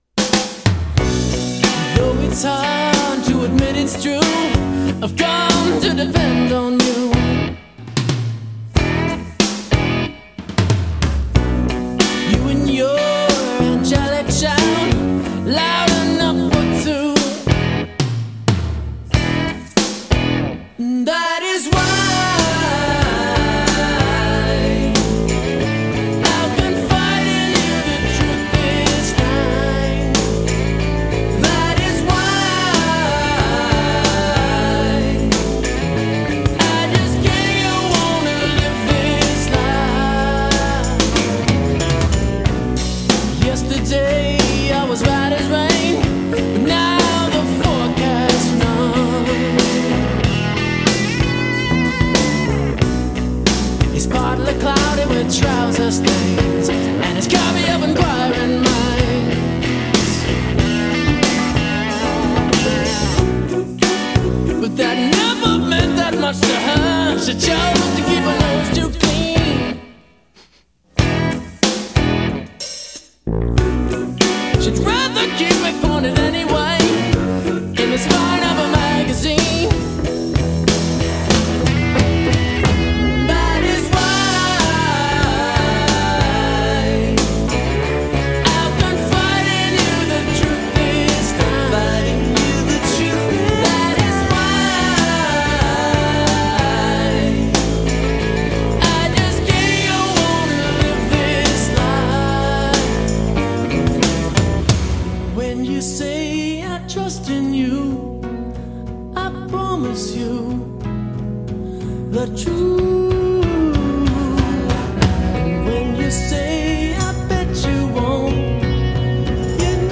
psychedelic-tinged power pop